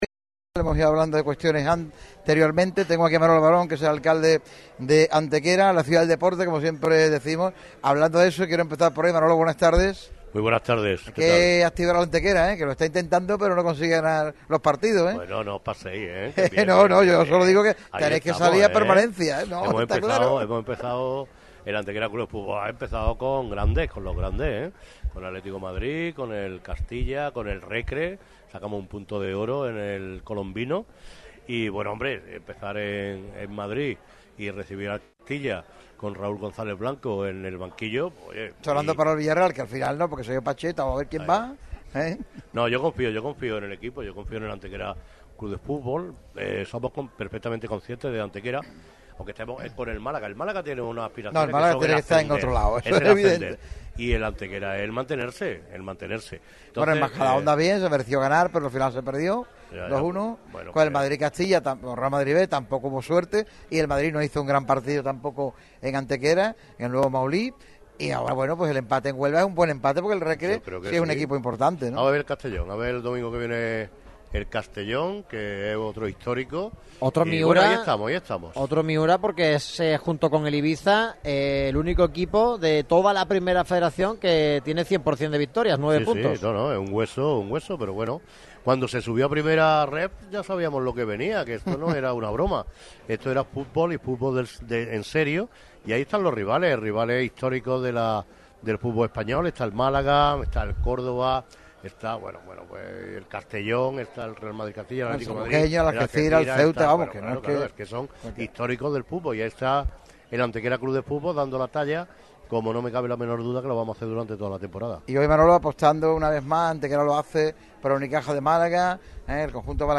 El alcalde de Estepona ha acudido al programa matinal de Radio MARCA Málaga realizado en el restaurante Trocadero Casa de Botes, en el Paseo de la Farola, 25.